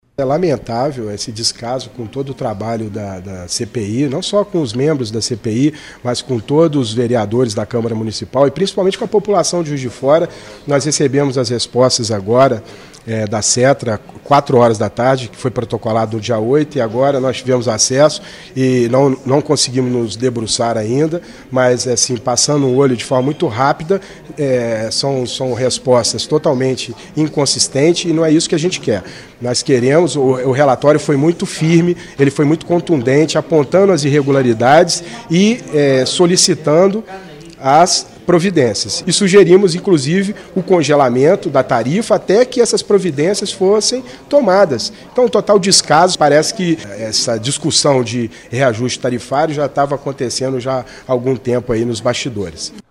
vereador Adriano Miranda